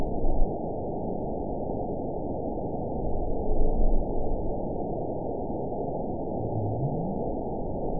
event 920007 date 02/06/24 time 15:43:17 GMT (1 year, 10 months ago) score 9.63 location TSS-AB01 detected by nrw target species NRW annotations +NRW Spectrogram: Frequency (kHz) vs. Time (s) audio not available .wav